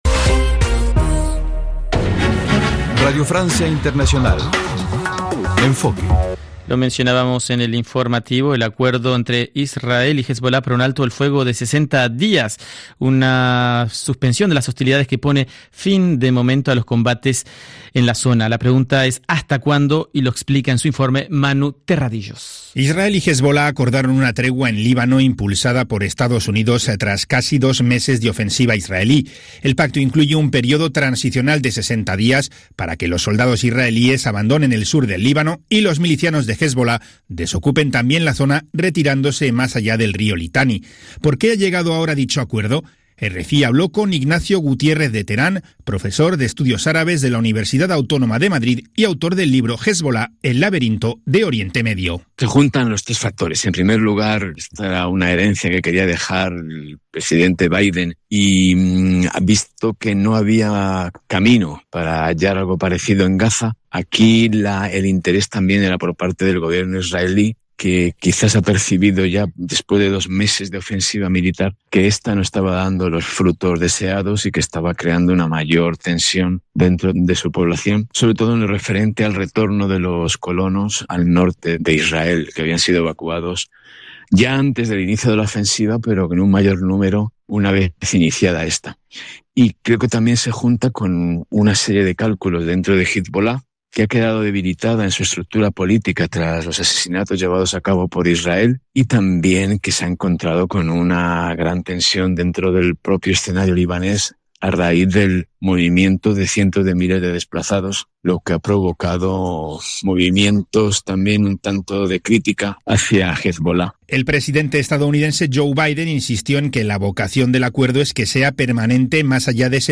Noticieros